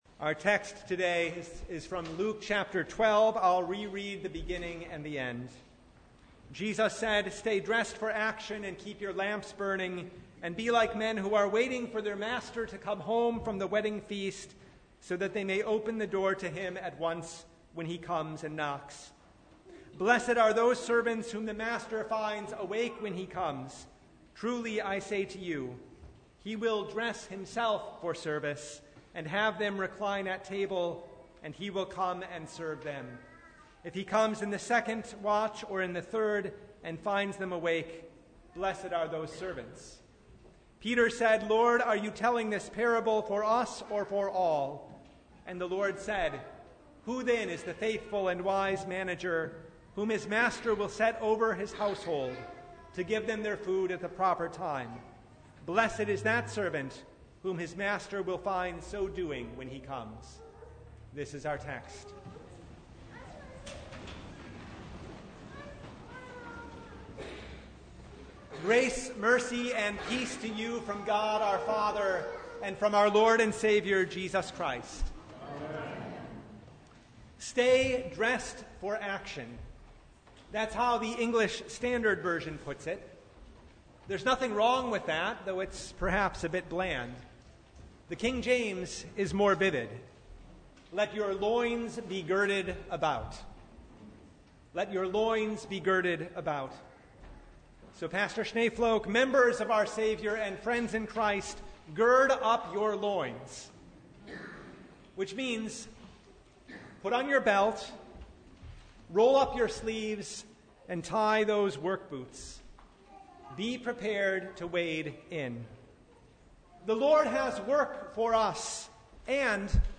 Sermon from Sixth Sunday in Apostles’ Tide (2022)